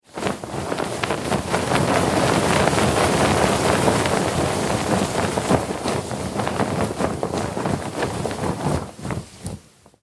Погрузитесь в атмосферу морских путешествий с коллекцией звуков паруса: шелест натянутой ткани, ритмичный стук волн о борт, крики чаек.
Звук Еще один шторм